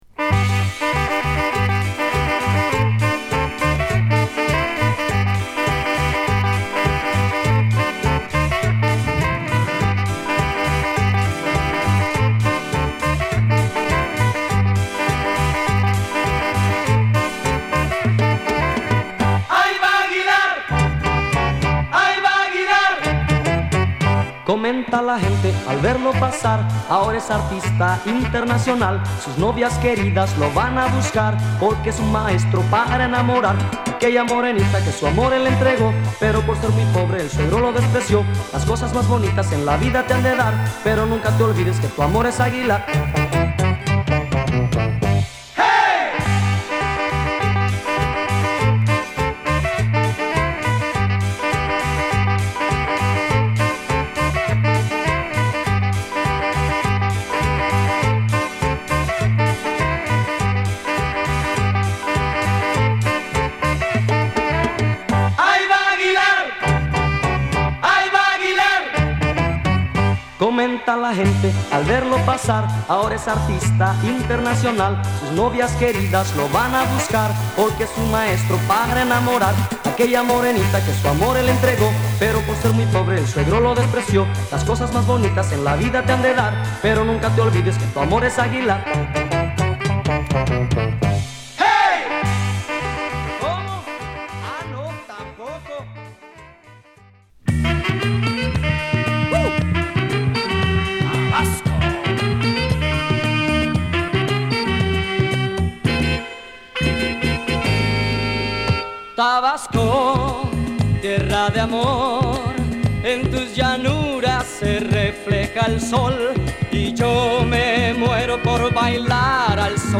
メキシコのバンド